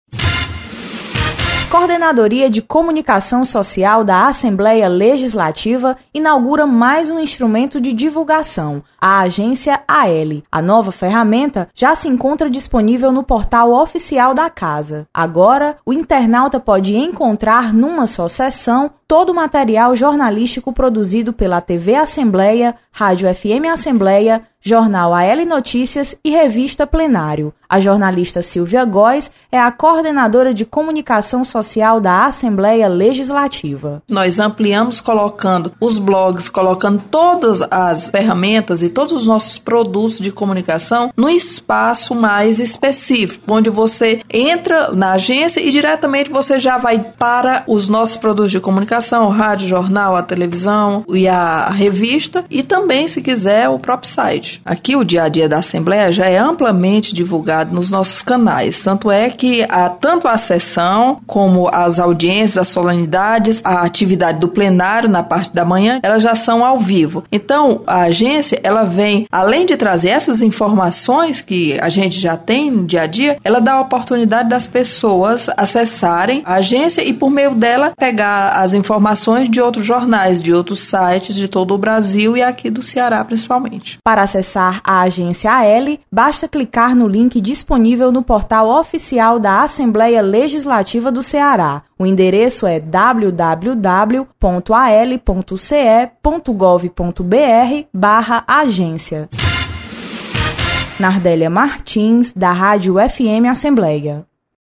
Apresentação da repórter